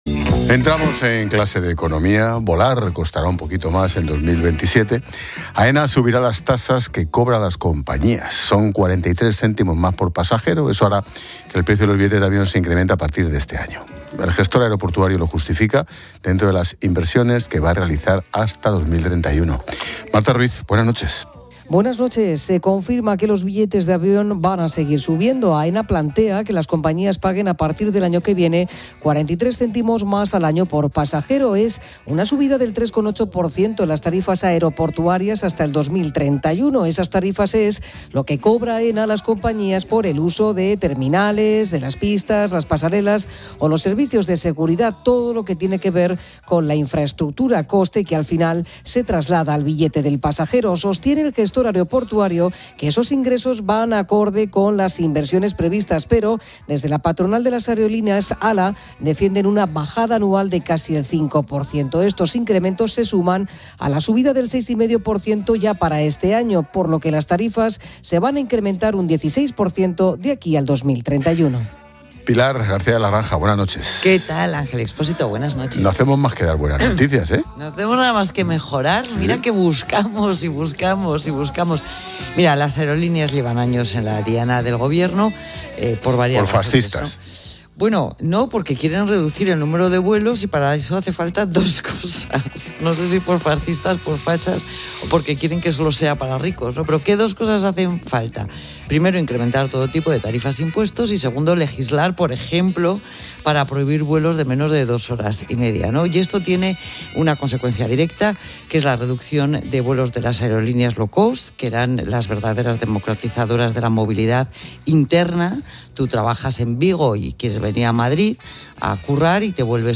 Expósito aprende en Clases de Economía de La Linterna con la experta económica y directora de Mediodía COPE, Pilar García de la Granja, sobre el aumento de las tasas aeroportuarias y el precio de los billetes de avión